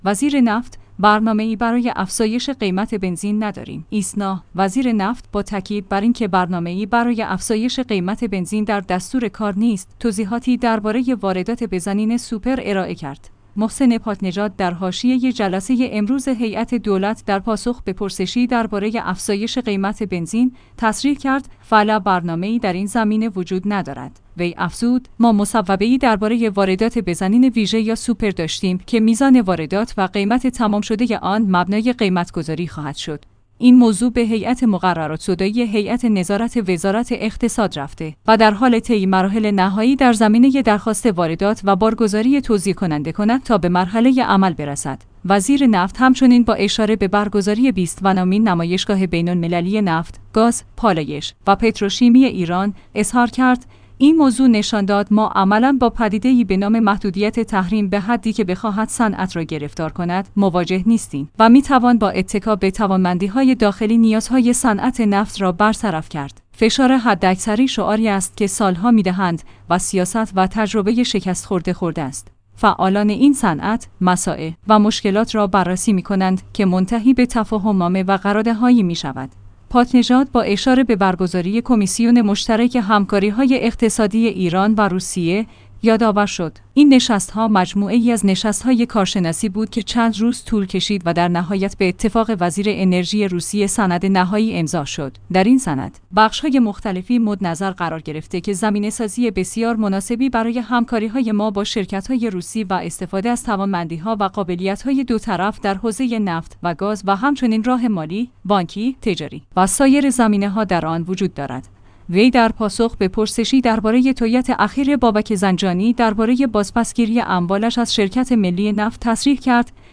محسن پاک‌نژاد در حاشیه جلسه امروز هیئت دولت در پاسخ به پرسشی درباره افزایش قیمت بنزین، تصریح کرد: فعلأ برنامه‌ای در این زمینه وجود ندارد.